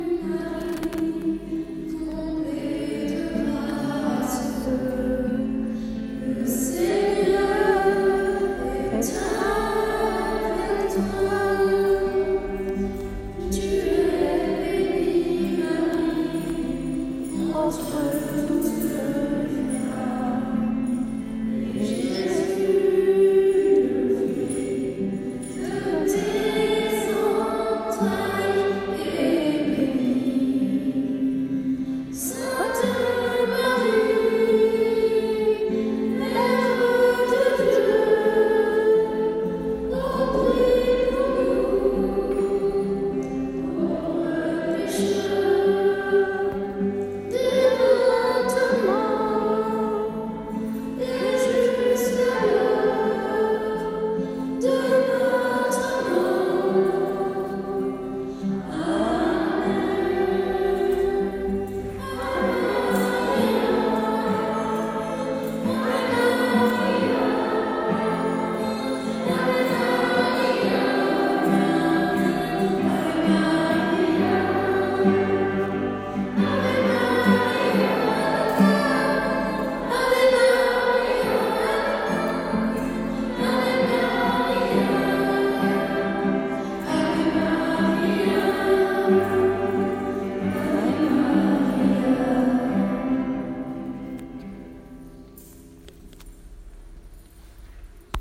Ce samedi 1er mai à 10h30 , a eu lieu à l’église de Corbigny un temps de louange proposé aux enfants du catéchisme et aux paroissiens.
à la guitare
Ce fut un temps de prière intense et joyeux .
(Mélodie : Groupe Glorius)